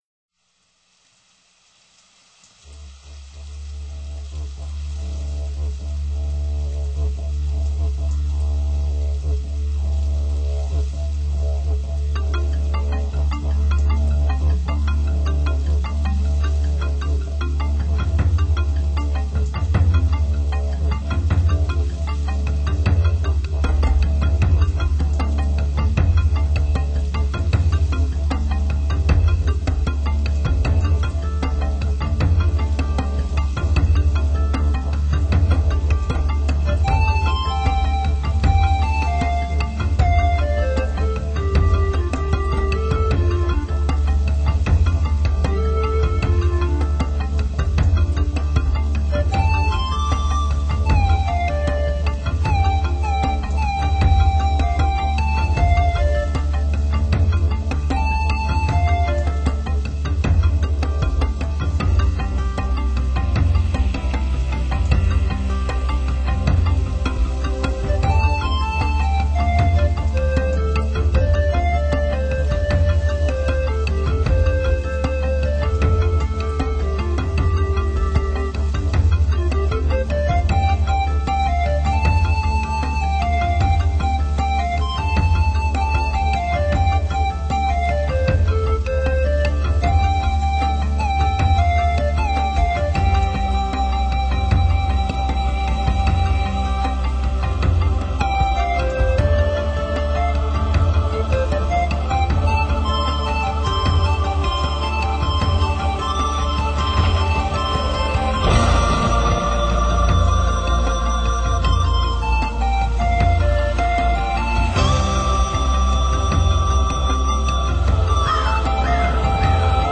柔和的女声中，轻藏隐隐的鼓韵。